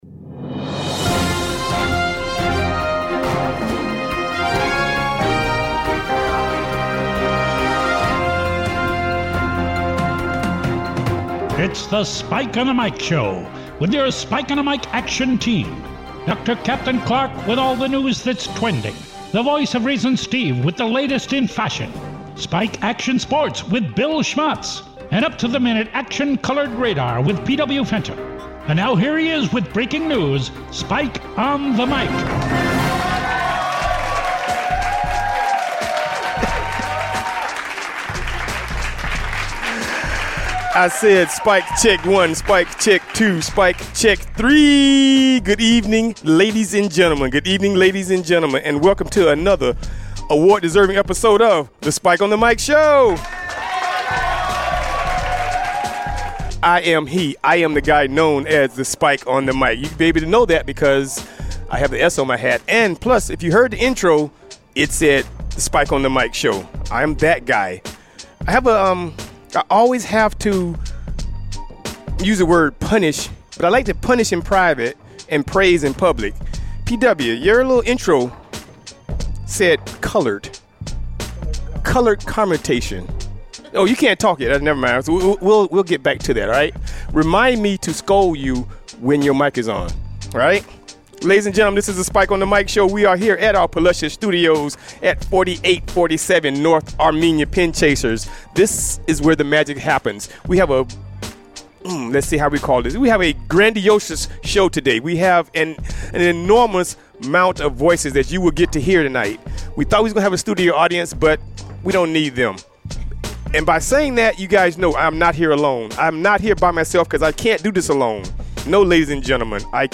Live from Pin Chasers in Tampa